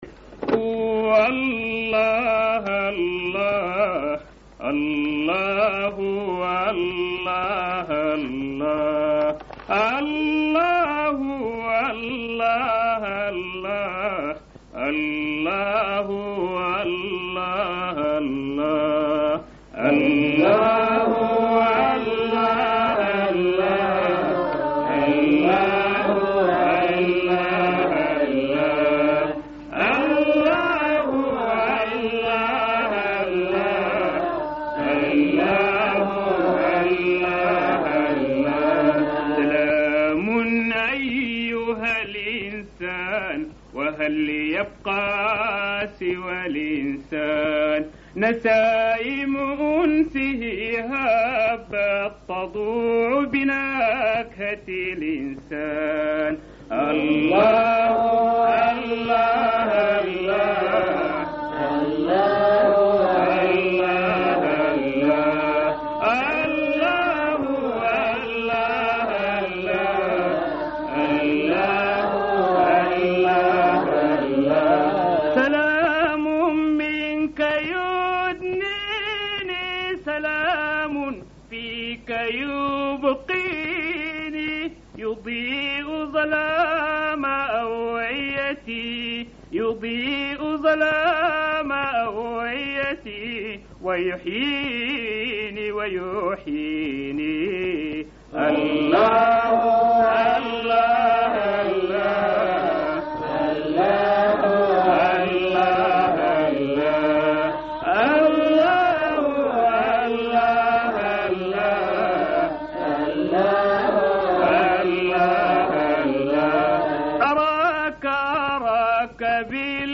جلسة إنشاد